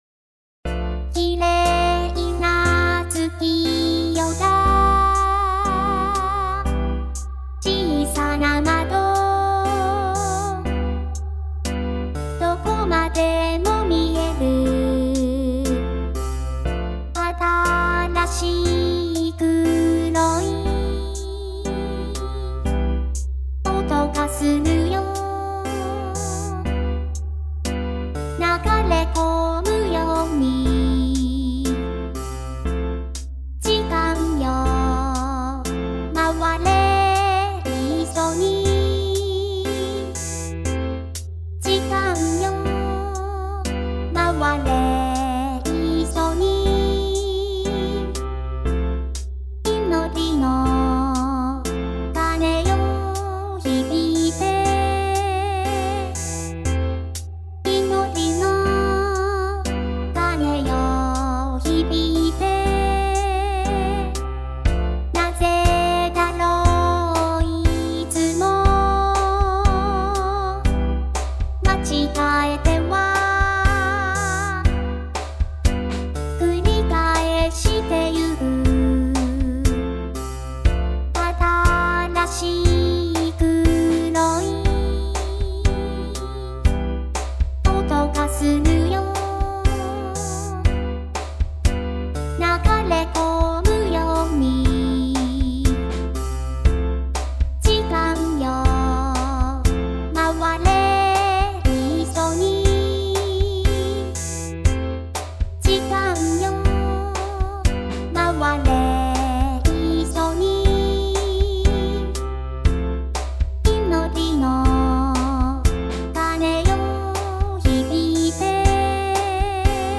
Vocaloid
Pop Dtm Synth
合成音声歌唱ソフトを使ってます。